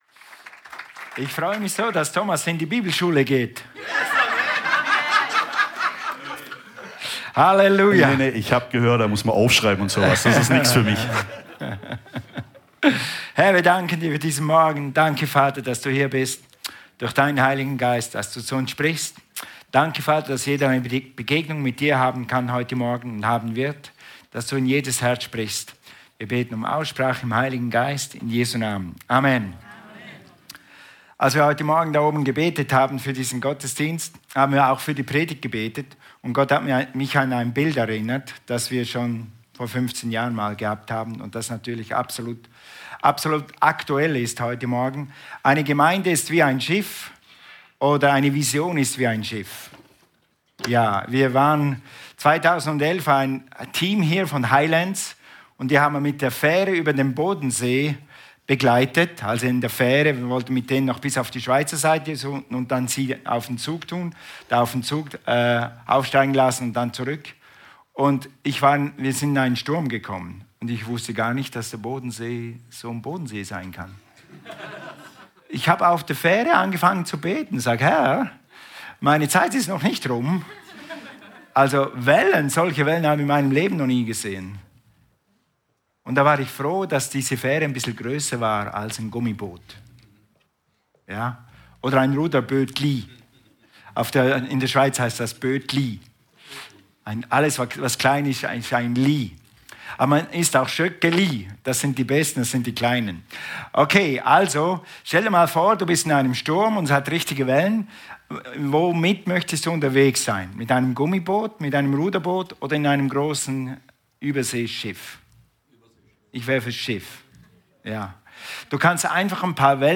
Alle Predigten aus den Sonntagsgottesdiensten